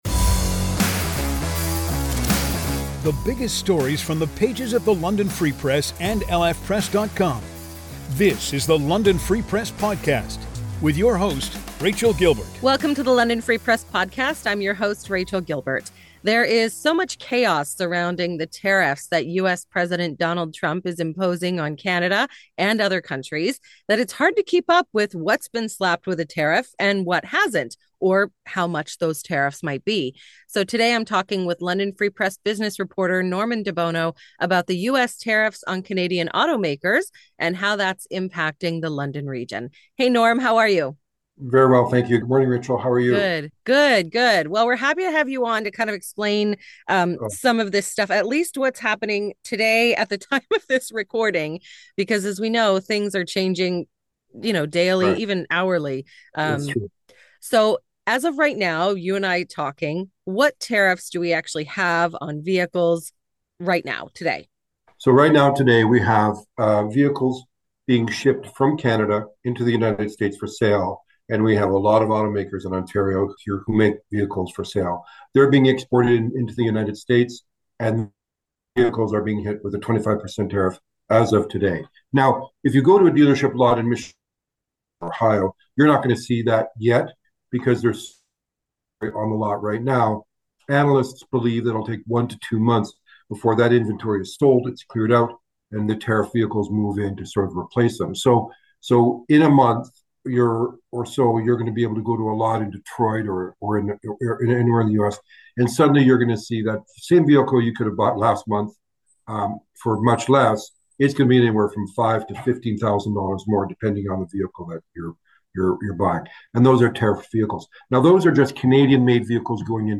… continue reading 170 episoade # Daily News # News Talk # News # The London Free Press Newsroom # On Covid19